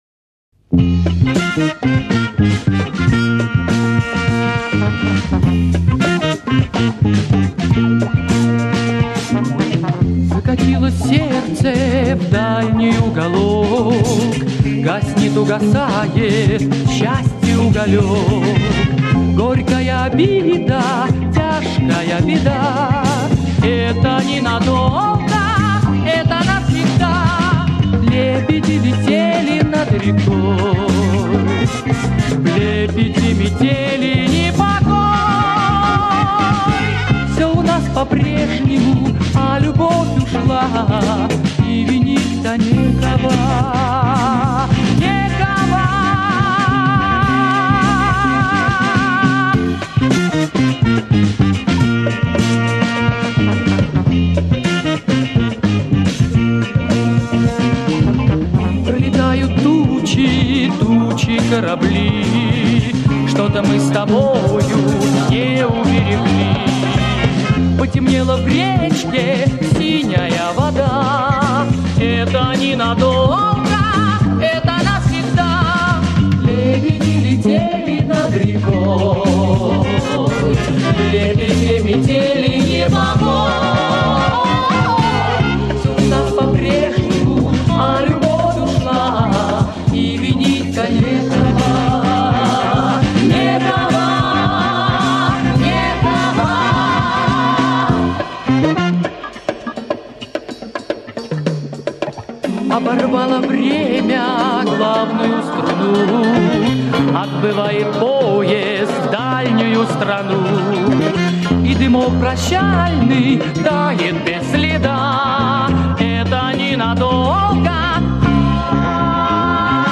На старой катушке 70-х.  Название тоже условное.
--- вокальный ансамбль